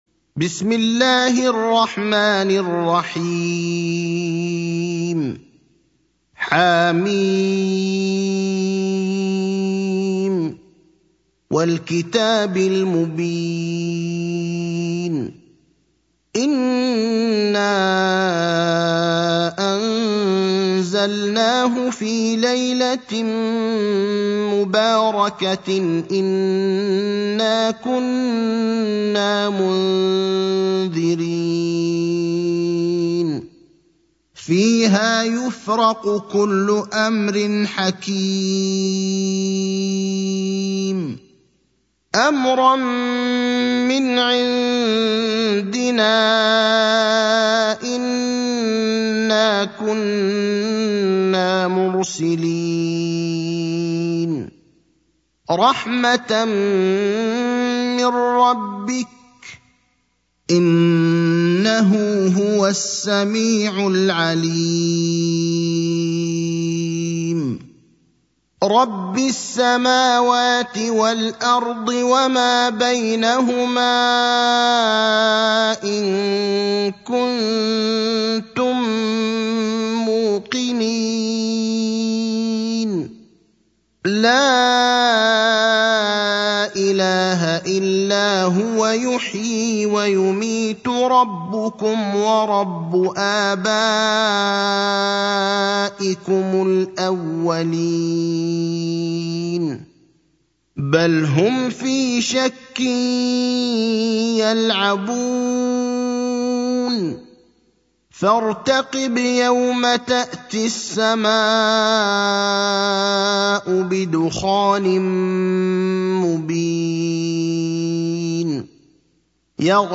المكان: المسجد النبوي الشيخ: فضيلة الشيخ إبراهيم الأخضر فضيلة الشيخ إبراهيم الأخضر الدخان (44)